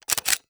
CAMERA_DSLR_Shutter_04_mono.wav